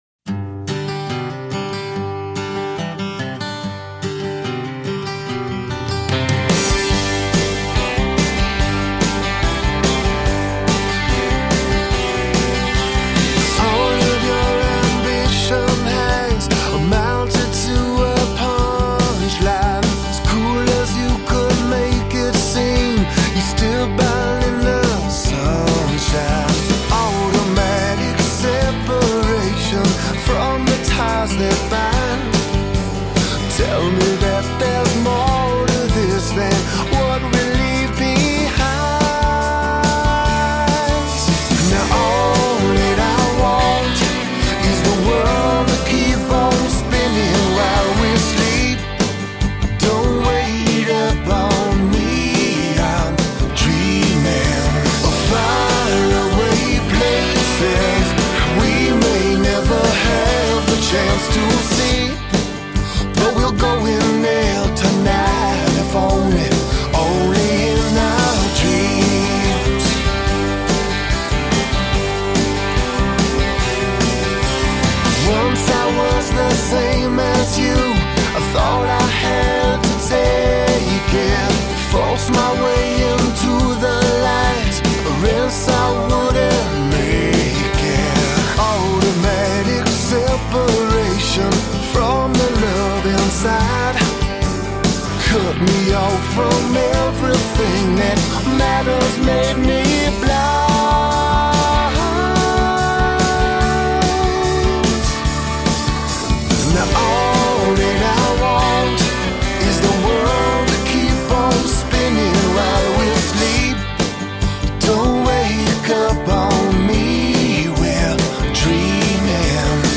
Masterfully nuanced aaa rock.
Tagged as: Alt Rock, Rock, Indie Rock